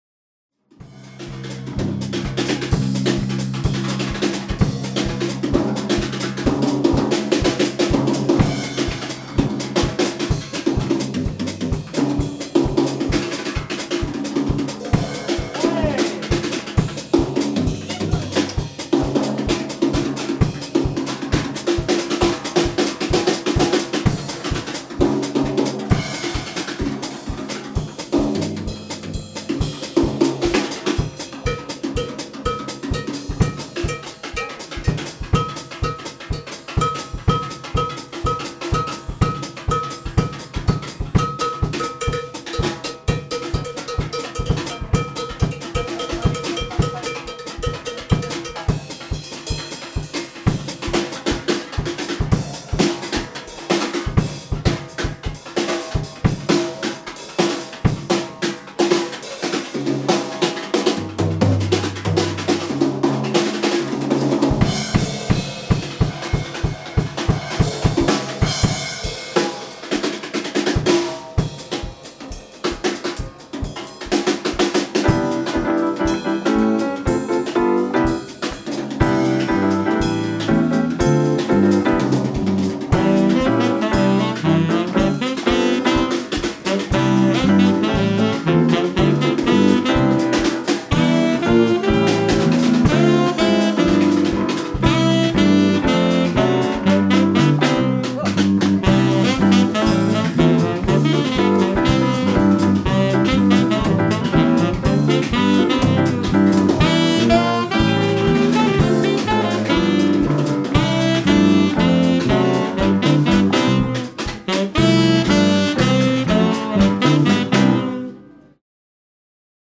hé bien pour l'instant je n'ai fait que enregistrer une batterie en overhead, en concert.
3 micros en tout.
Contexte jazz.
J'aime beaucoup cette prise live très naturelle, je ne joue pas vraiment de jazz, mais j'apprecie les vraies prises acoustiques, je ne vois pas l'interêt des prises tom par tom hyper compressées, on se retrouve souvent avec le même resultat qu'une V-Drum.
Les T-Bone RB500 (les rubans).
SaintThomas(drums&end).mp3